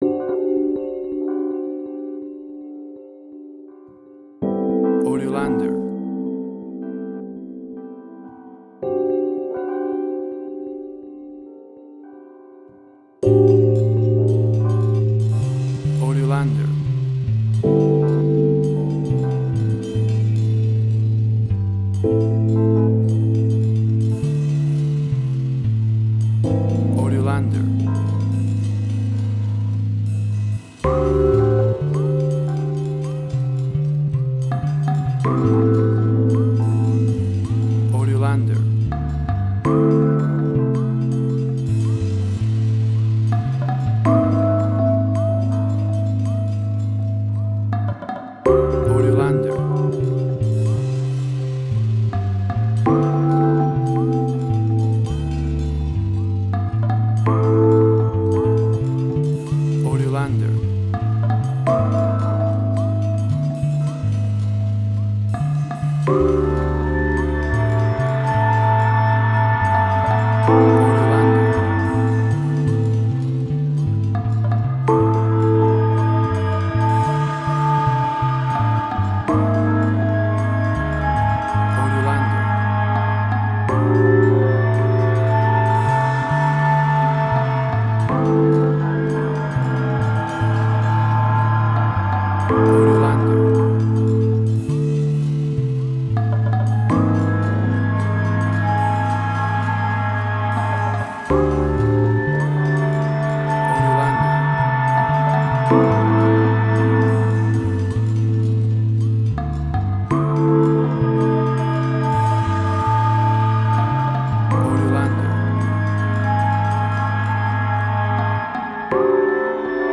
Suspense, Drama, Quirky, Emotional.
Tempo (BPM): 55